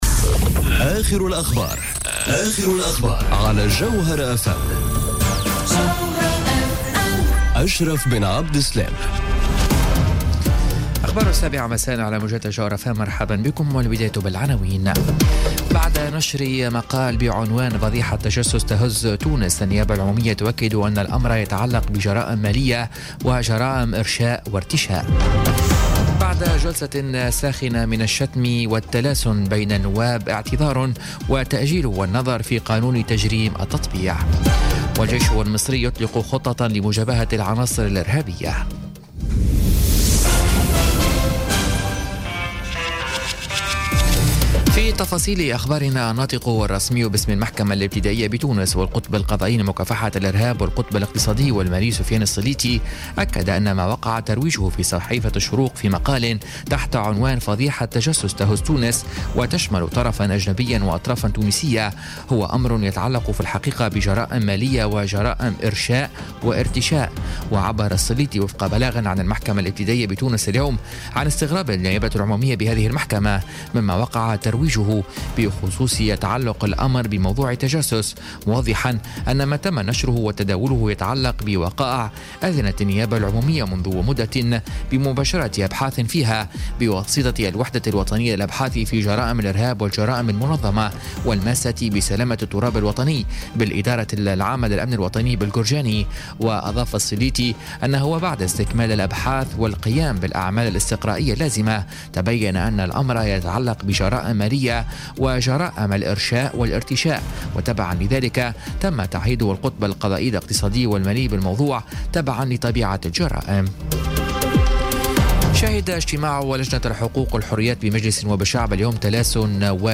نشرة أخبار السابعة مساءً ليوم الجمعة 9 فيفري 2018